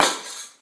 glassSmash.ogg